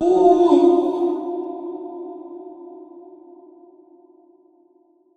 TS Vox_19.wav